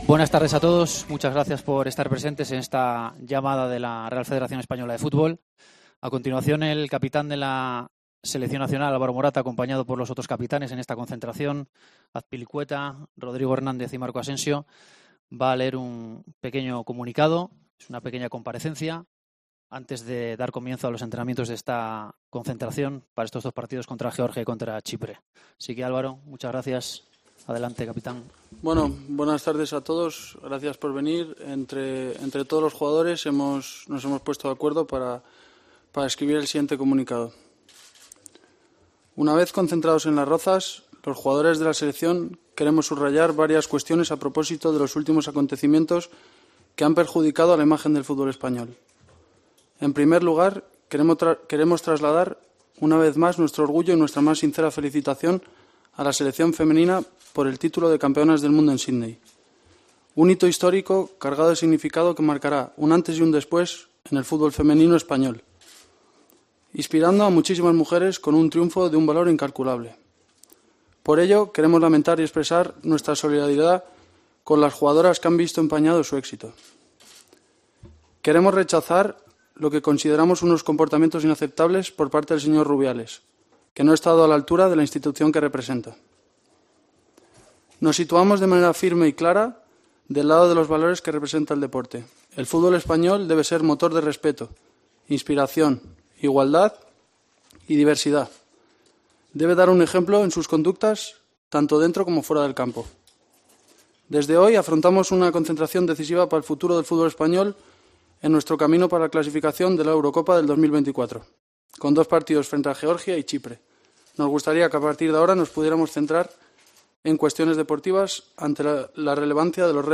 Los capitanes de la selección española, en rueda de prensa
Álvaro Morata leyó un comunicado condenando las actitudes de Luis Rubiales antes de iniciar los entrenamientos en la concentración de Las Rozas.